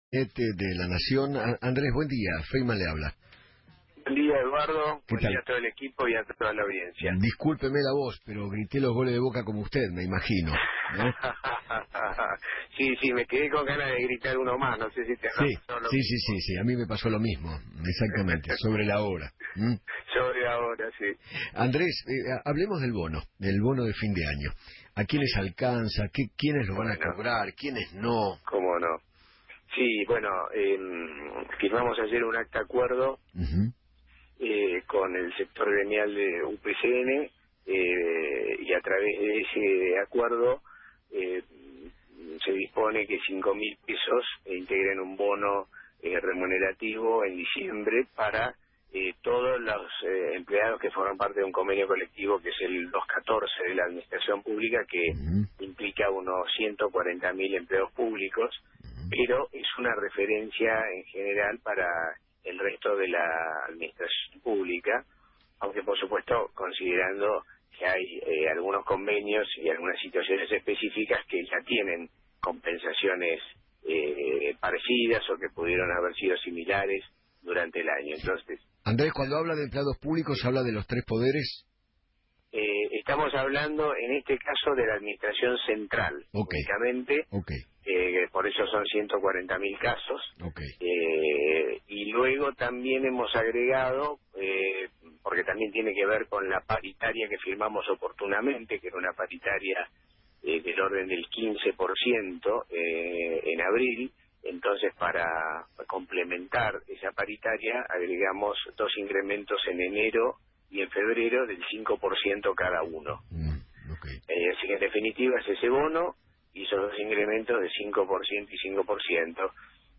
Andrés Ibarra, vicejefe de gabinete de la Nación, habló en Feinmann 910 y afirmó que  “Con el sector gremial de UPCN dispusimos un bono de 5 mil pesos remunerativo para todos los empleados que forman parte de un convenio colectivo, 142 mil empleos públicos, de la administración central.